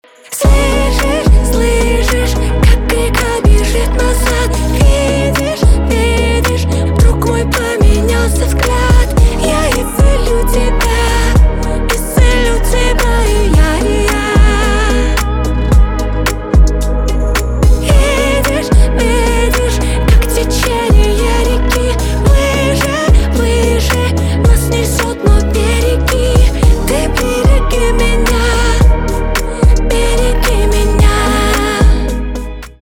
поп
басы , битовые